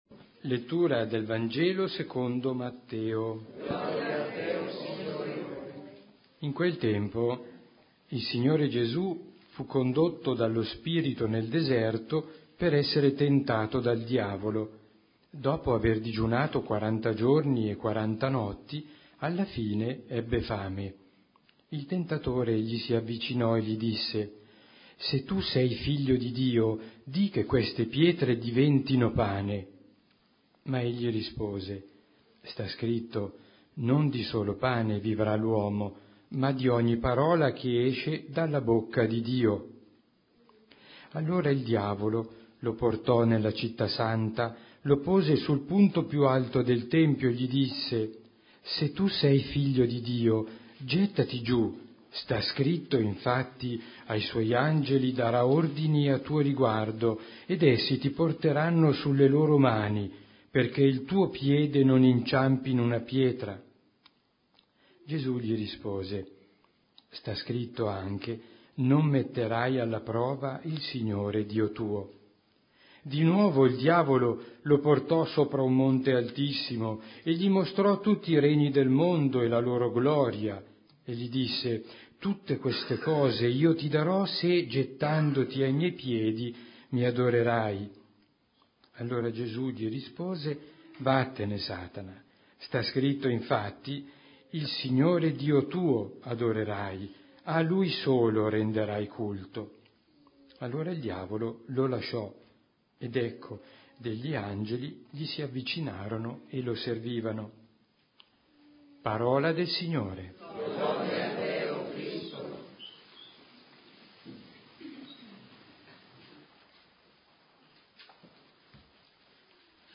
Omelia della Santa Messa del giorno